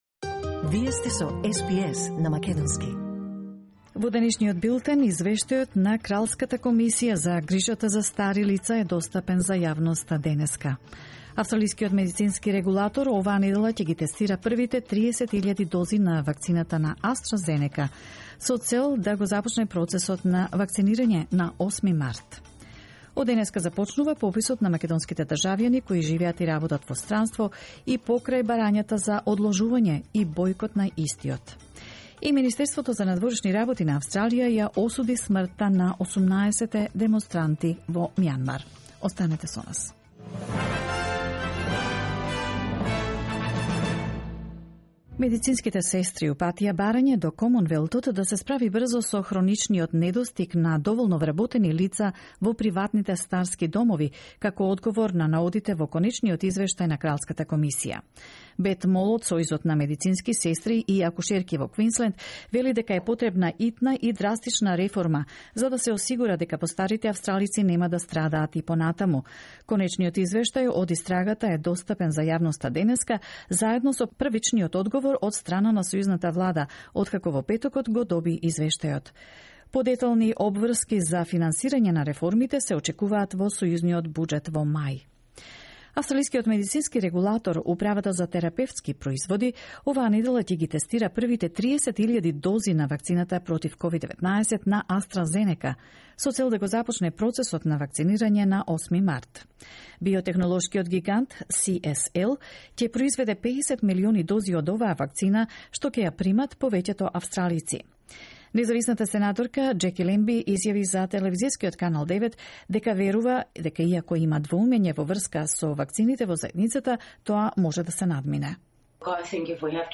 SBS News in Macedonian 1 March 2021